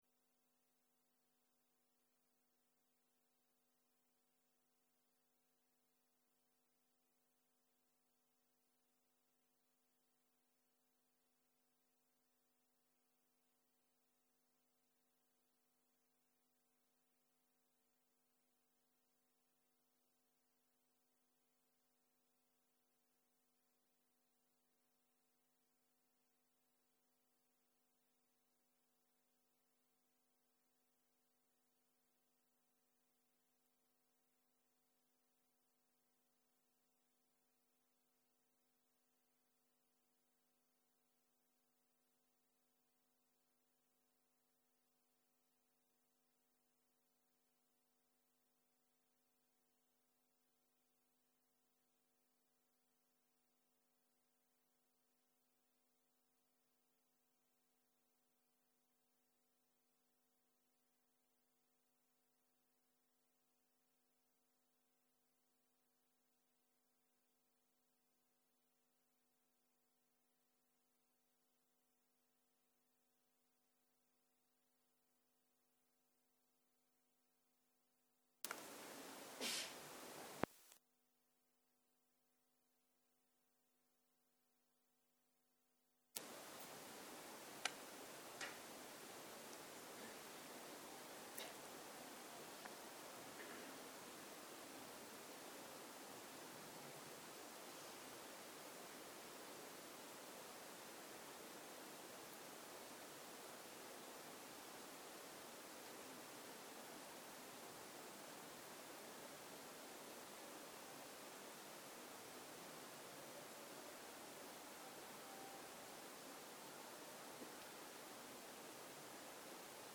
ערב - מדיטציה מונחית
Guided meditation